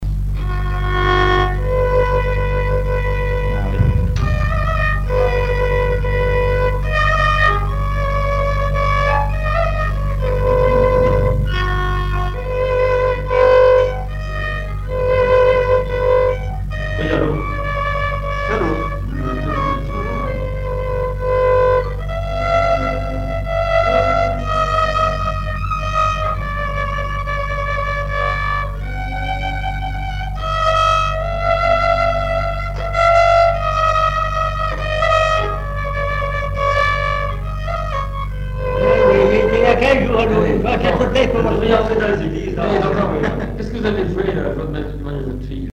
prière, cantique
Genre strophique
chansons populaires et instrumentaux
Pièce musicale inédite